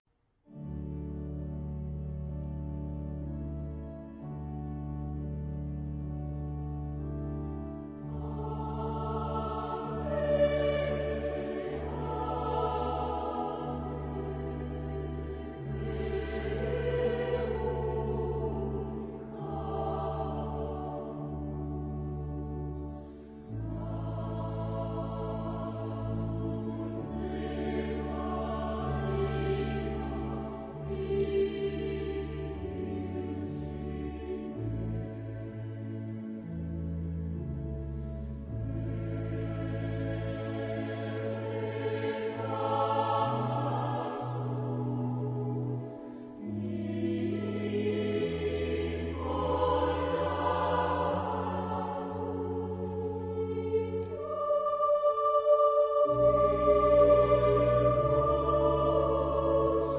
SATB (4 voix mixtes) ; Partition complète.
Sacré. Motet.
Consultable sous : JS-18e Sacré avec instruments